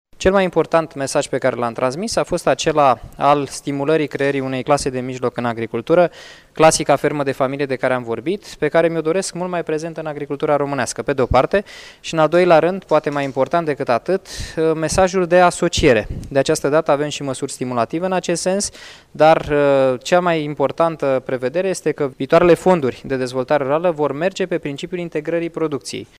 Reprezentanții Comisiei Europene au trimis o serie de observații Guvernului de la București referitoare la Planul Național de Dezvoltare Rurală din România, integrarea producției și înființarea asociațiilor și cooperativelor agricole, a anunţat, astăzi, la Iași de ministrul de resort Daniel Constantin, care a participat la conferința cu tema Fermele mici si asocierea.